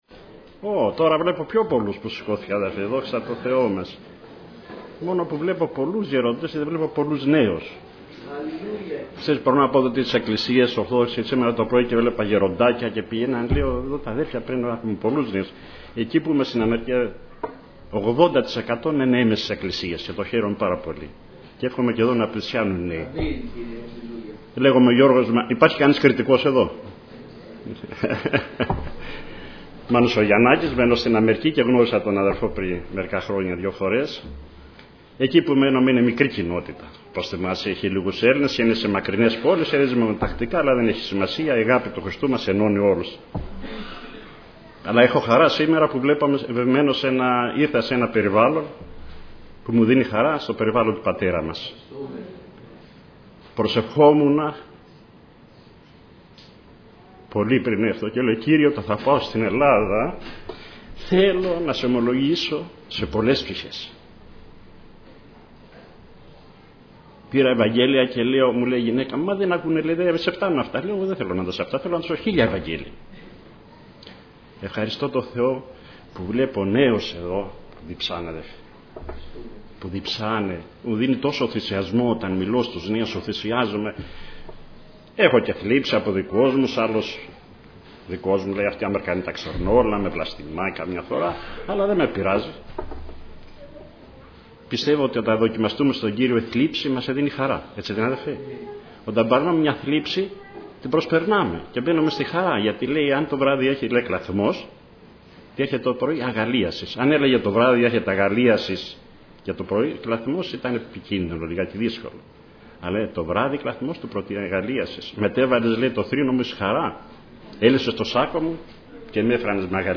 Κυριακάτικα Ημερομηνία